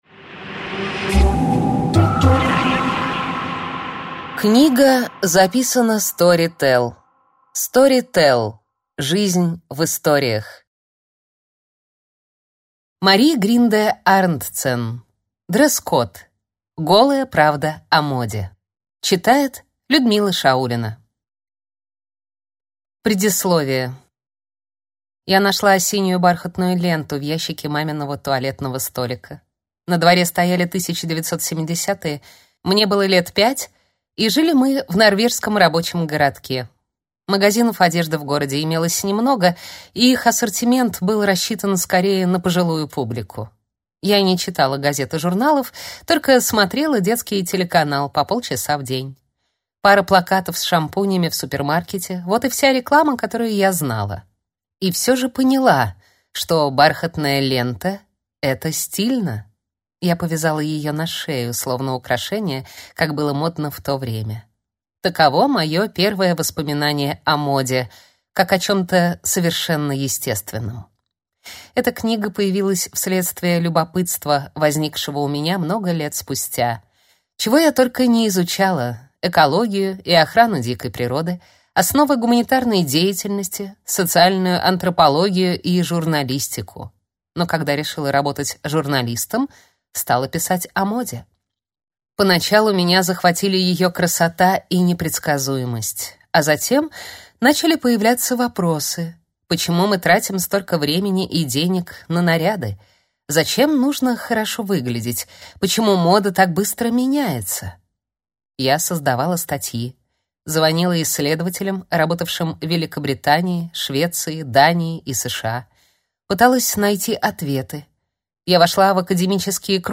Аудиокнига Дресс-код. Голая правда о моде | Библиотека аудиокниг